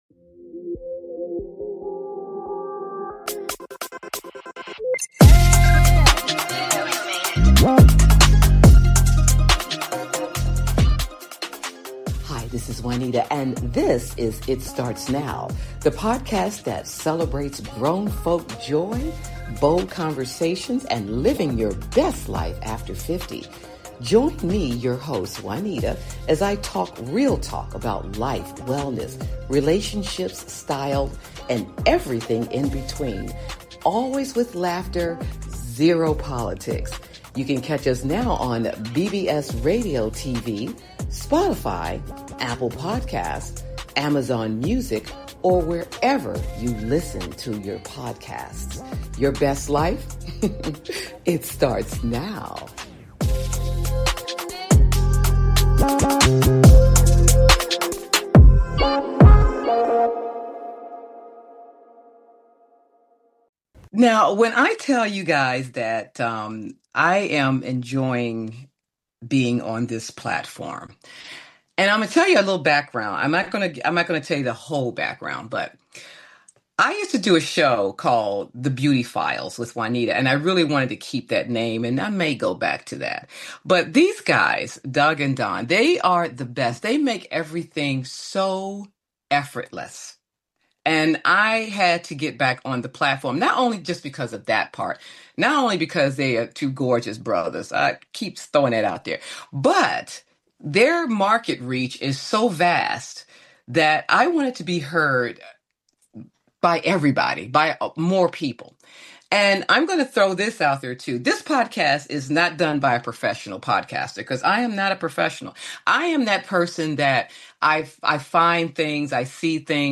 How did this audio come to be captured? Podcast Bio : is a lively, conversational podcast that flips the script on aging.